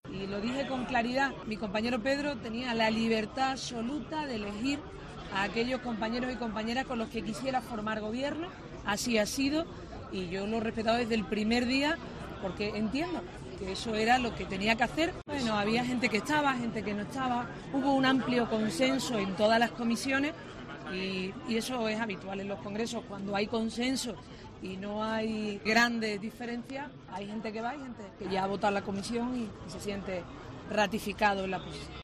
En declaraciones a los medios a la salida del Palacio Municipal de Congresos, en la segunda jornada de la asamblea socialista, Díaz ha comentado que Sánchez es "libre de formar equipo con los compañeros que crea para fortalecer el proyecto colectivo del PSOE en España".